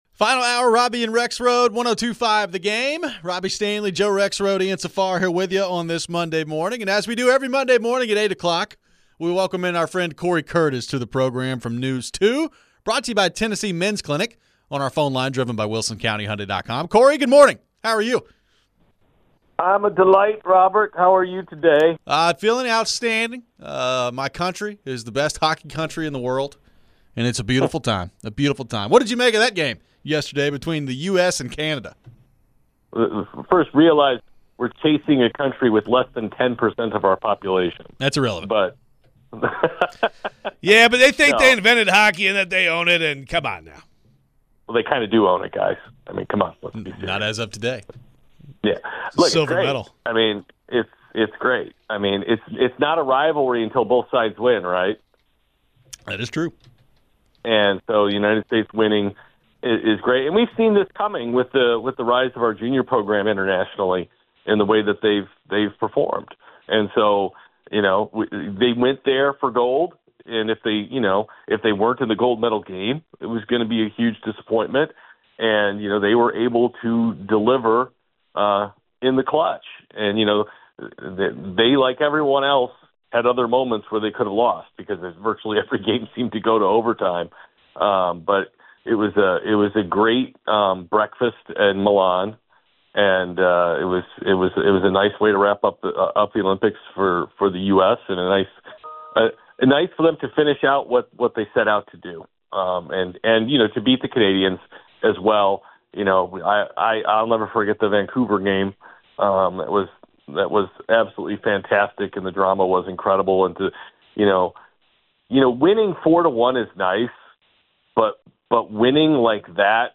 We get back into the US gold medal win and take your phones. What does this win mean big picture for the game of hockey and the NHL?